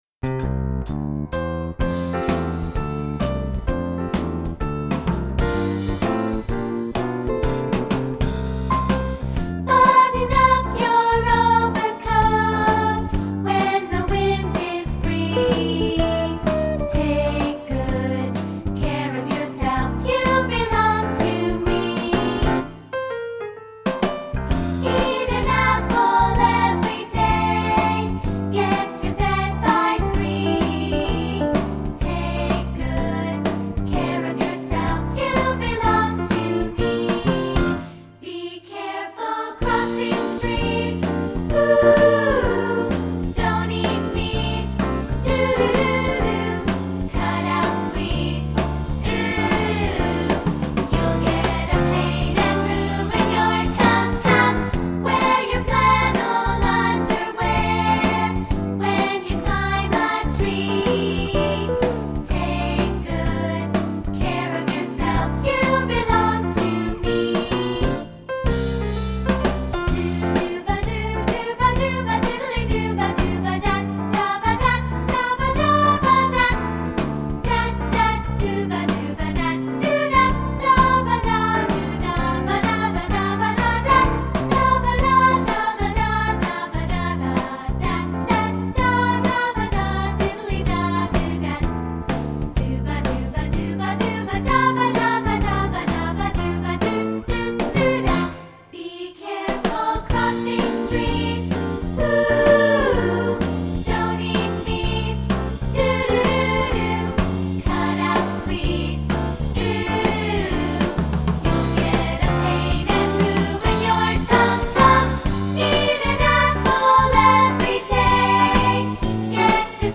Voicing: 2-Part